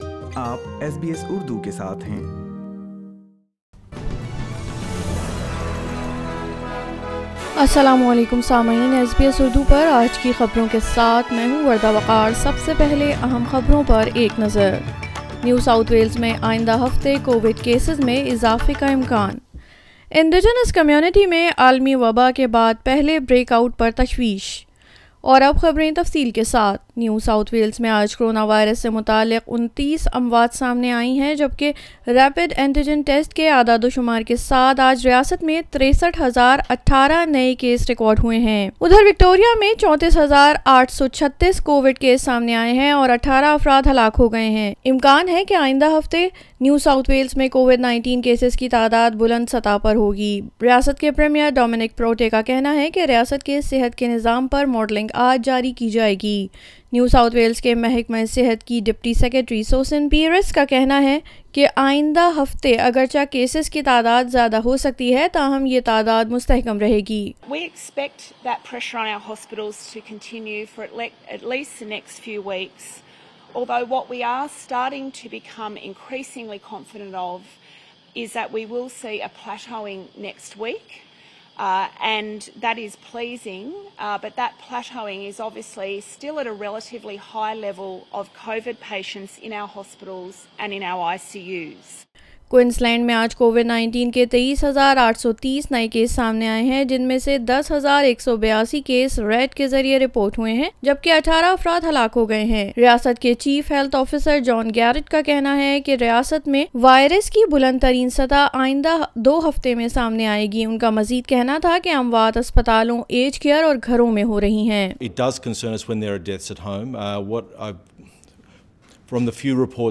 New South Wales expects new COVID-19 cases to plateau next week. Concerns for Indigenous communities now grappling with their first outbreaks of the pandemic. For more details listen to Urdu news